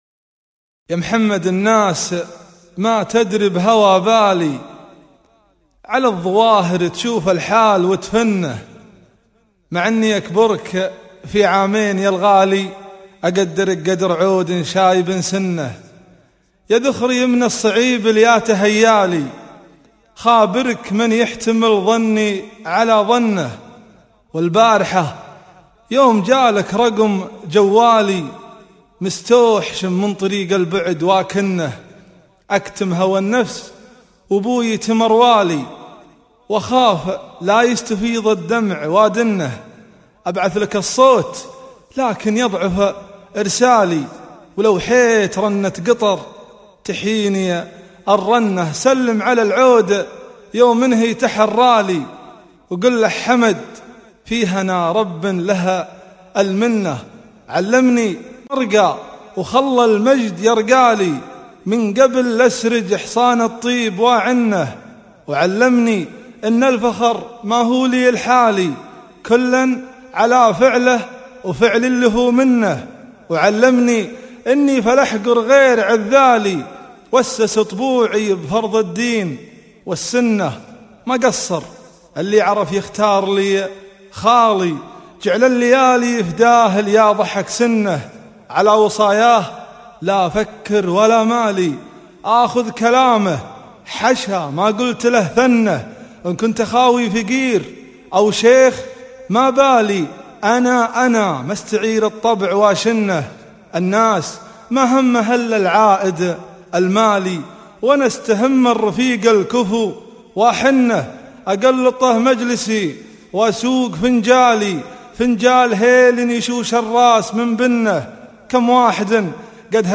yam7md_bdwn_mwsyka.mp3